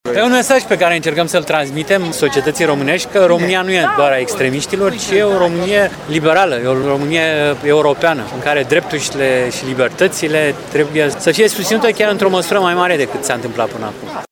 Miting pentru Europa în Piața Victoriei din Timișoara